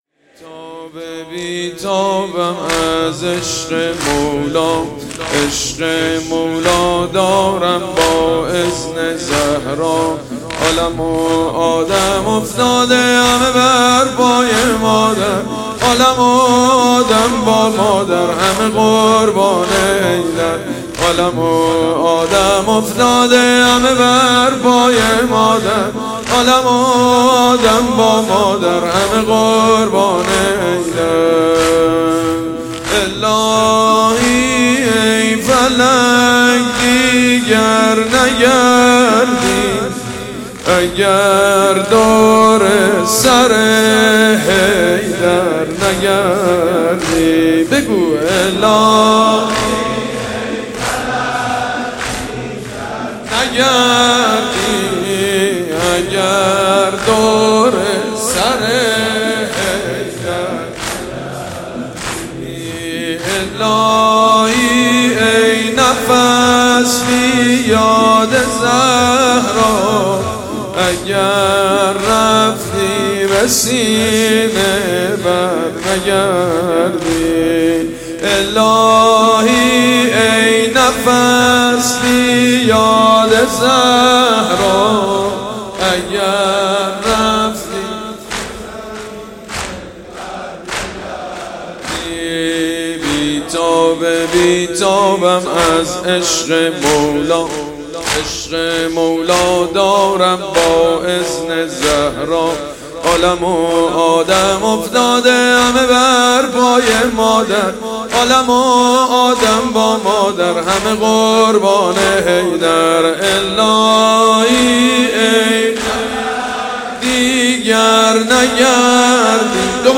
حاج سید مجید بنی فاطمه - شب اول فاطمیه دوم سال 95 - بی تاب بی تابم از عشق مولا
• حاج سید مجید بنی فاطمه - شب اول فاطمیه دوم سال 95 - بی تاب بی تابم از عشق مولا (زمینه جدید).mp3